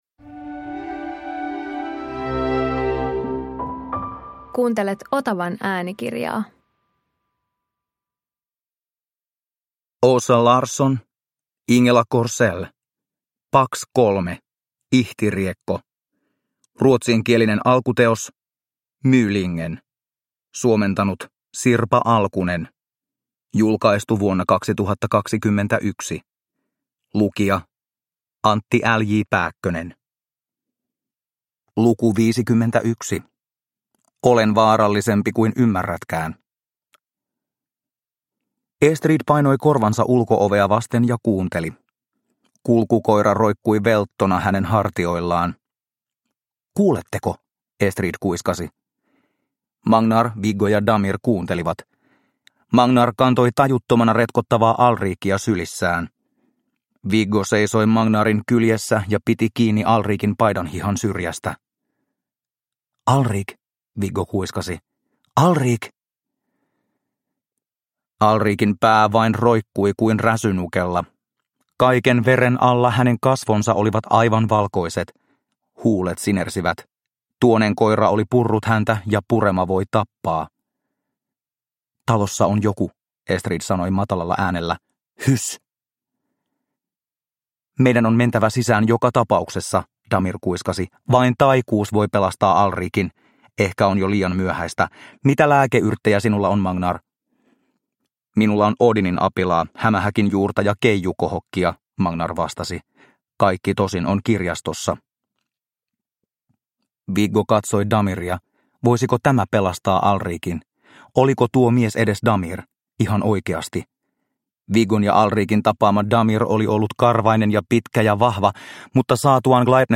Pax 3 - Ihtiriekko – Ljudbok – Laddas ner